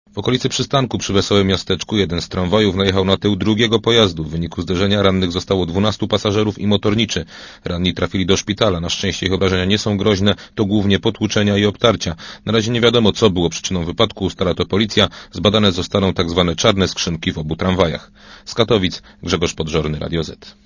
Posłuchaj relacji reportera Radia Zet (80kB)